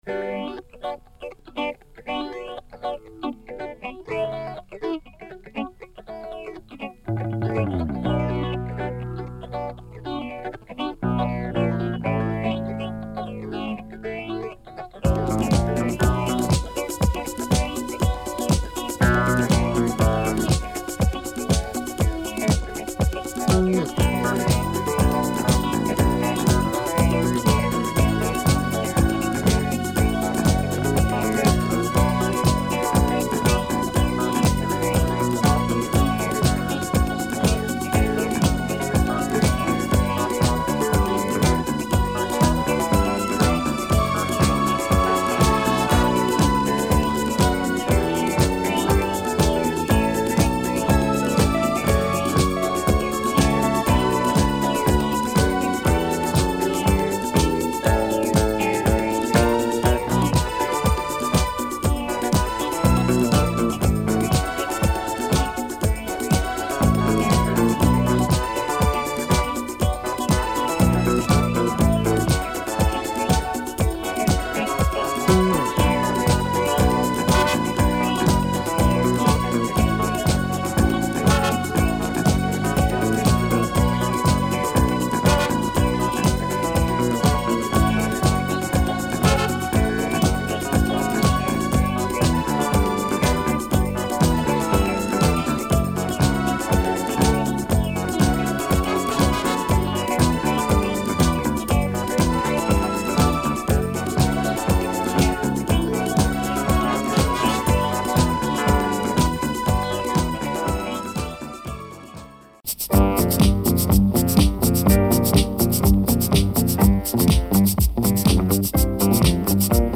Killer groove and disco from Venezuela !